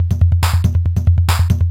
DS 140-BPM A4.wav